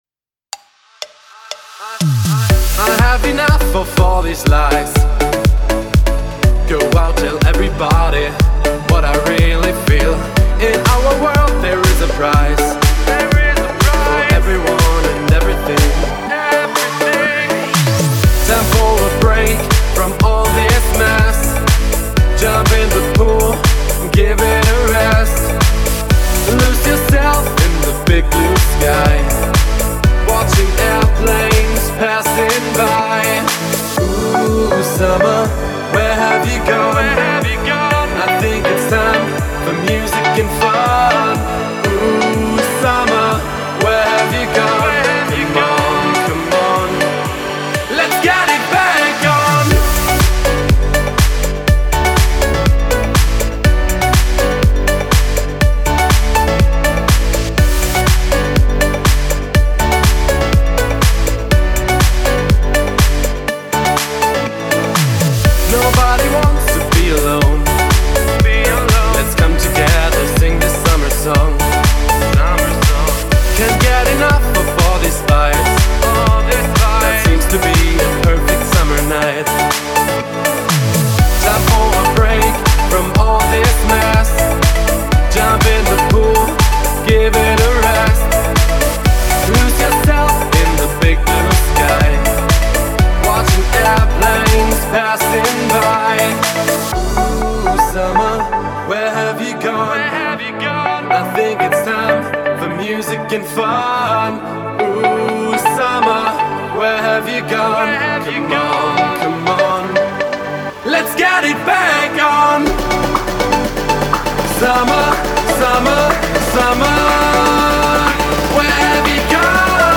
это трек в жанре поп с элементами электронной музыки.